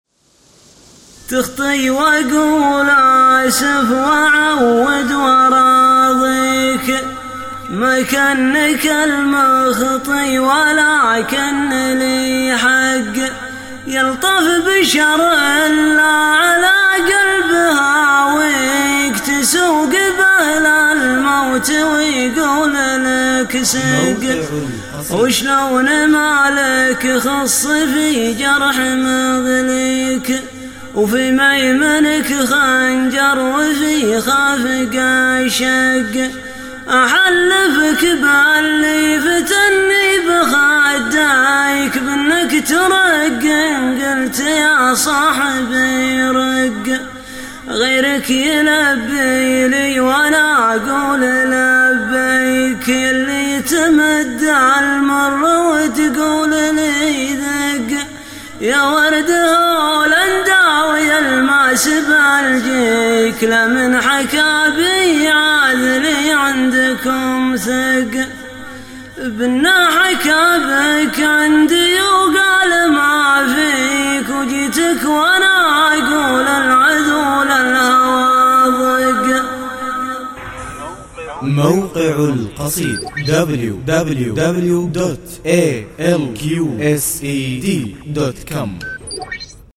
شــيــلــه